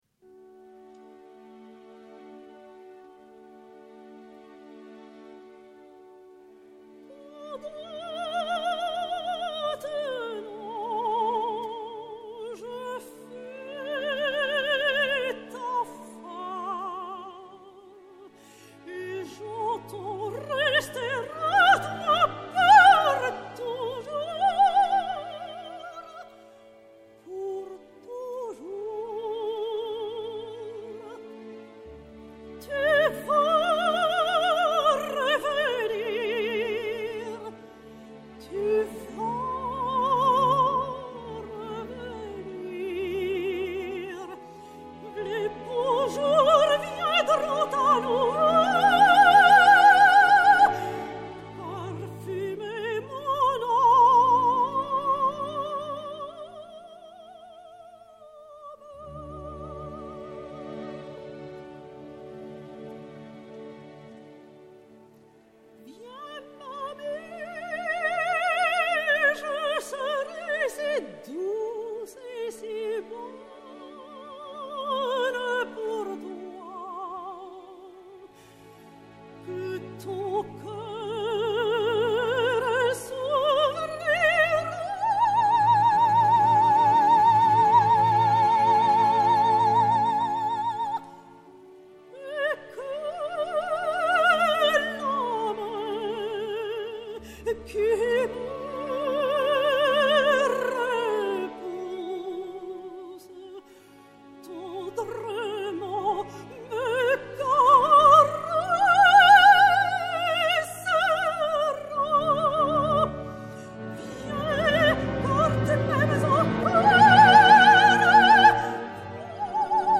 Renée Doria (Fanny) et Orchestre de la Garde Républicaine dir Roger Boutry